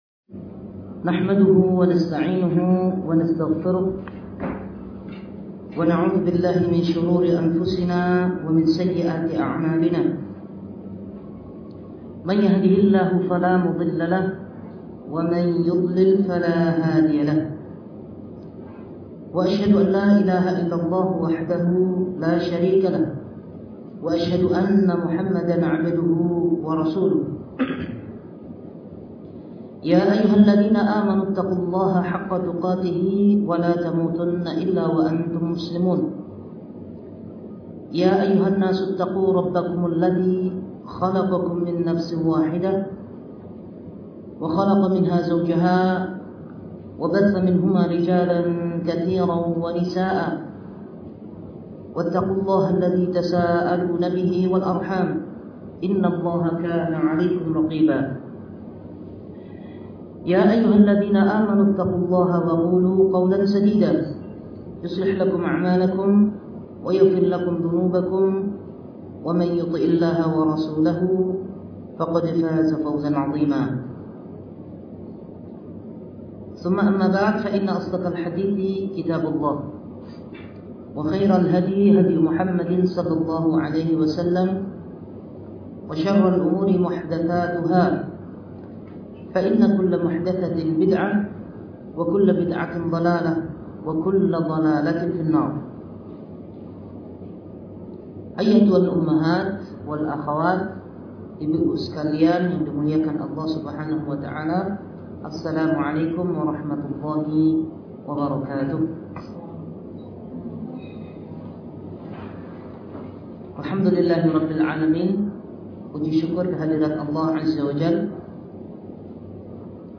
Video live: Facebook Assunnah Qatar
Kajian-tafsir-Al-maidah-Al-Wakra.mp3